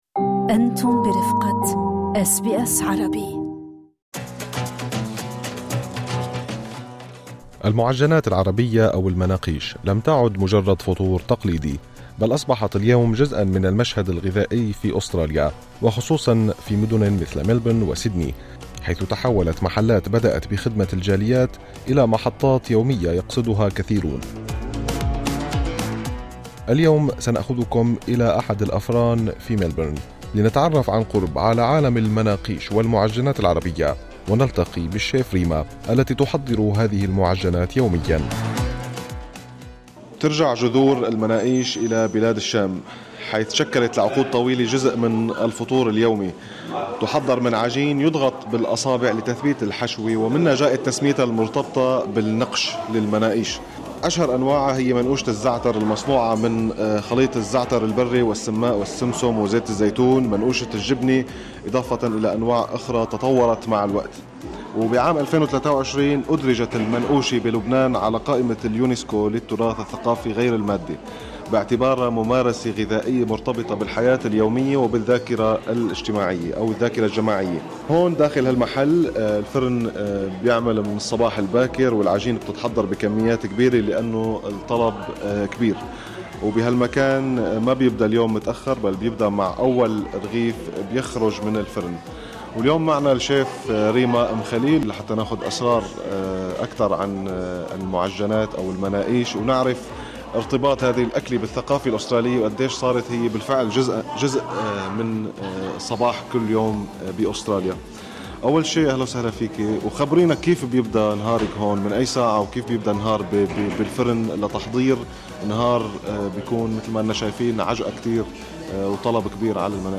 في جولة داخل أحد أفران المناقيش في ملبورن، تفتح هذه الحلقة نافذة على عالم المعجنات العربية، من جذورها الثقافية، إلى طريقة تحضيرها، وصولًا إلى حضورها المتزايد في الحياة اليومية الأسترالية.